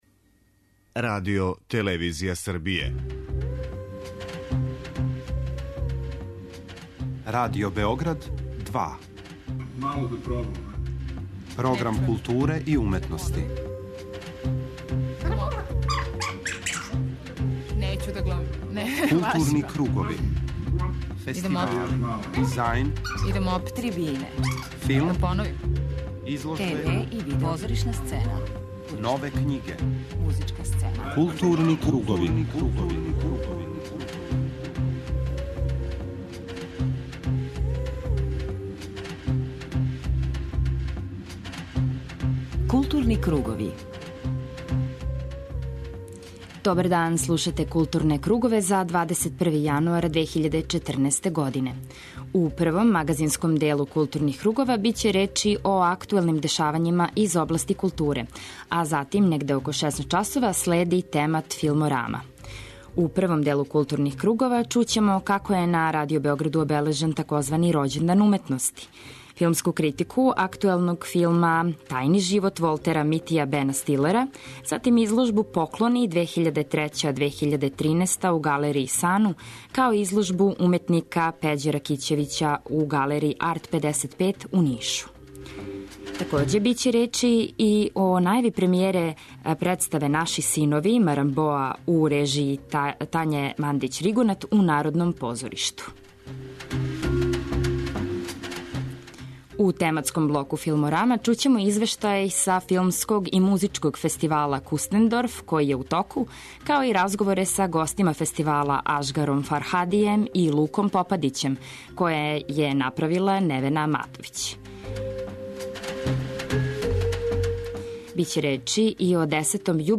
Чућете и разговоре са младим редитељима, главним звездама фестивала, чији се филмови приказују у Такмичарском програму и "боре" за награде Златно, Сребрно и Бронзано јаје, као и за награду "Вилко Филач".